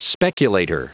Prononciation du mot speculator en anglais (fichier audio)